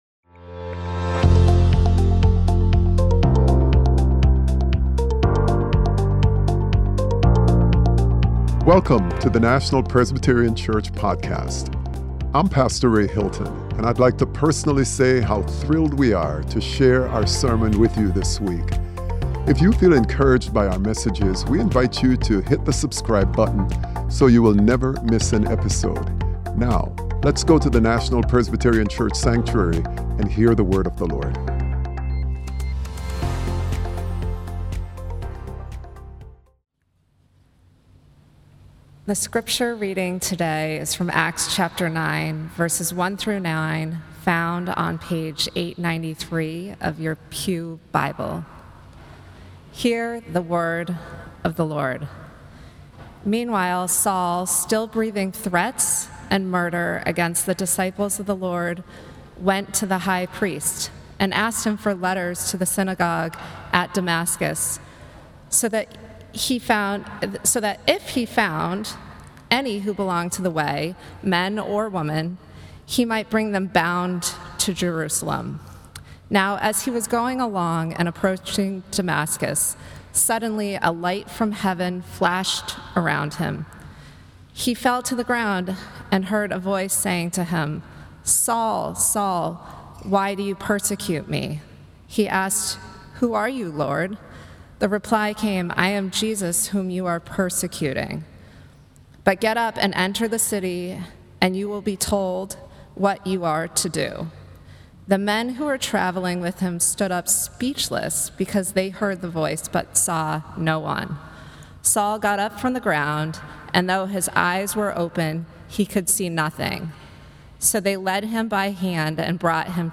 Join us for a transformative sermon series. Each week, we will explore different aspects of growing in faith and deepening our relationship with Christ.